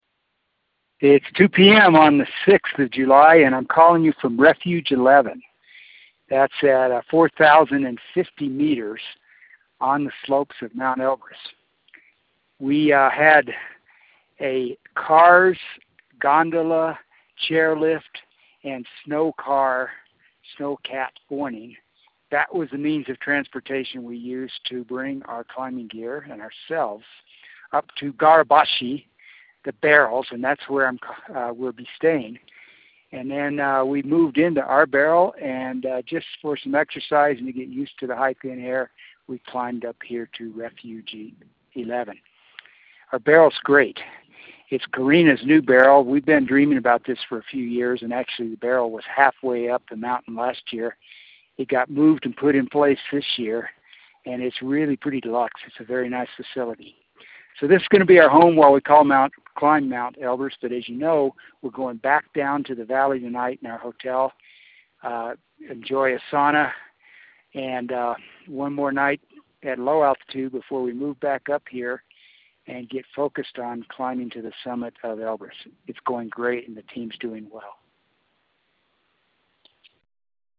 Elbrus Expedition Dispatch